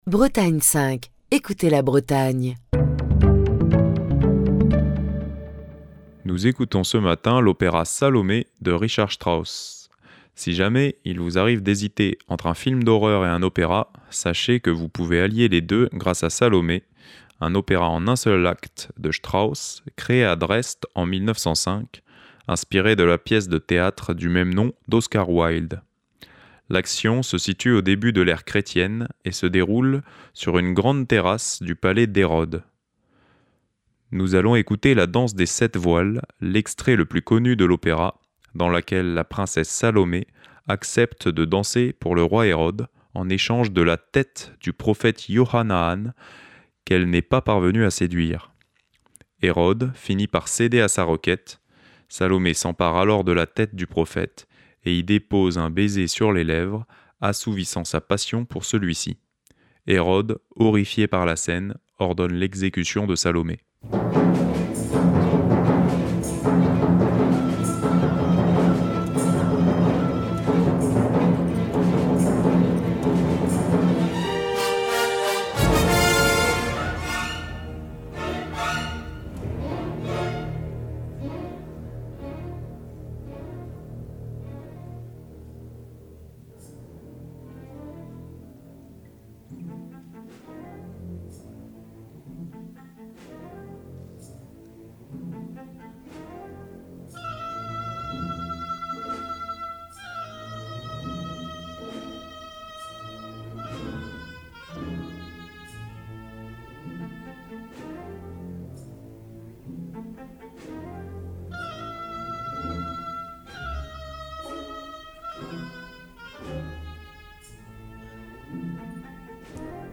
Nous allons écouter "La Danse des sept voiles", l’extrait le plus connu de l’opéra, dans lequel la princesse Salomé accepte de danser pour le roi Hérode en échange de la tête du prophète Jochanaan, qu’elle n’a pas réussi à séduire.